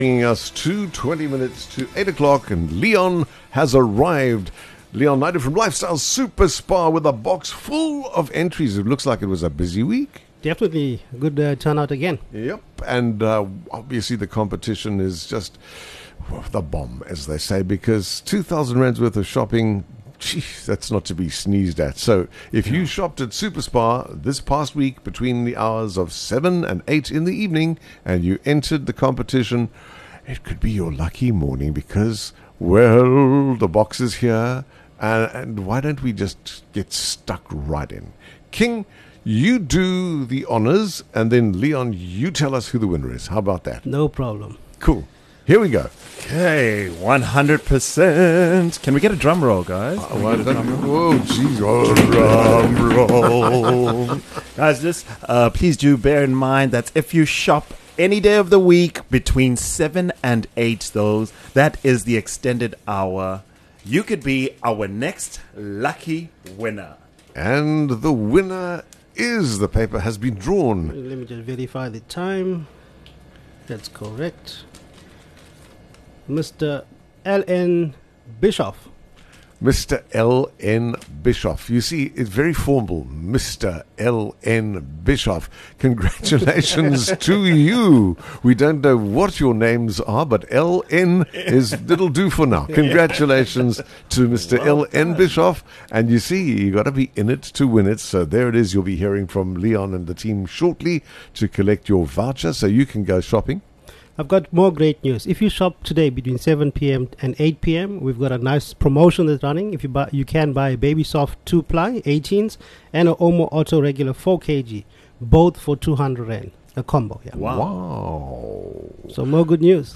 Arrive between 7:00 PM and 8:00 PM and stand a chance to enter and win a R2000 shopping voucher! Listen to our interview to learn how to enter and what is required.